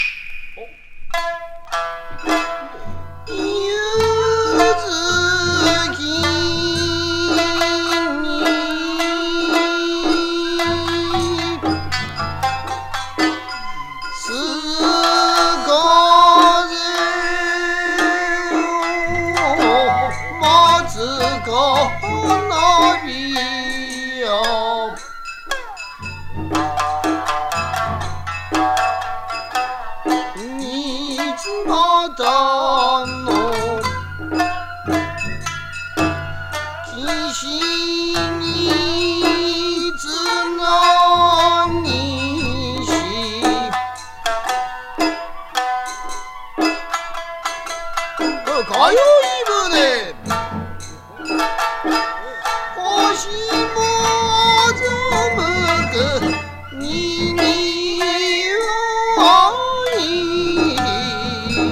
浄瑠璃を語る太夫と三味線弾きで構成される三味線音楽の一種、常磐津節のレコード。